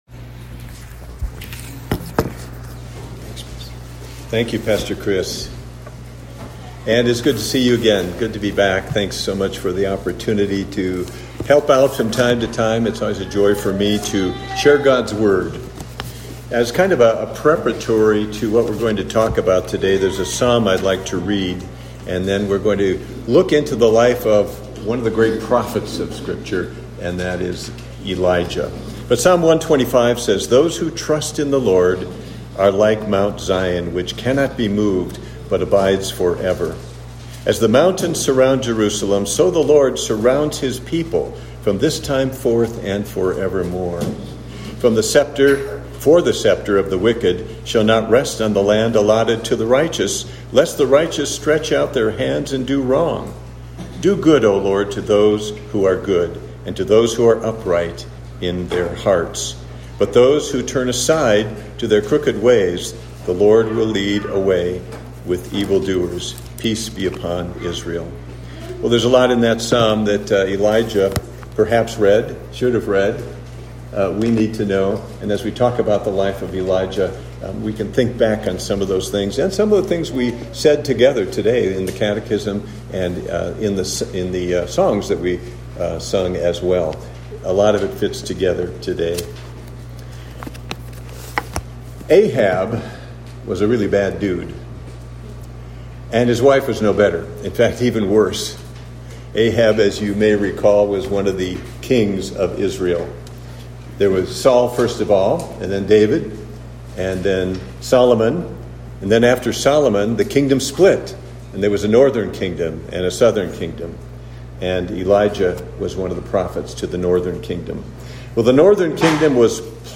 Series: Guest Preacher
Service Type: Morning Service